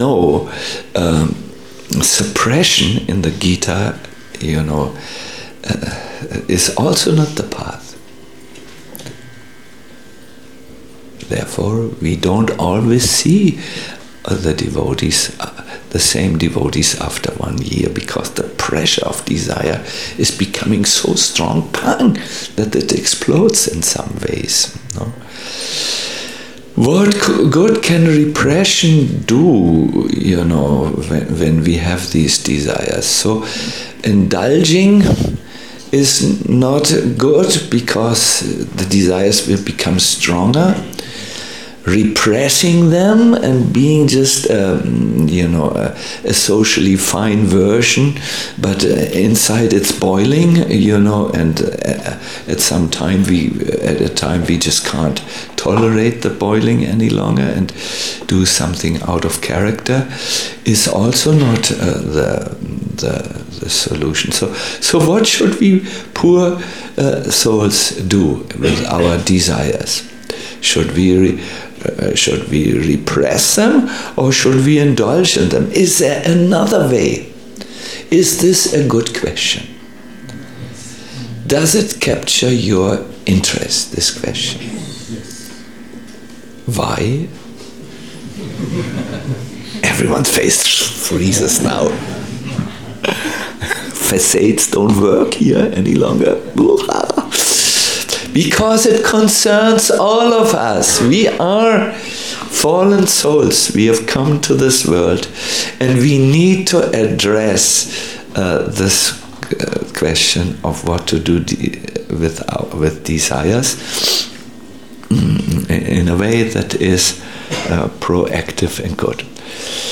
A lecture
Bhaktivedanta Manor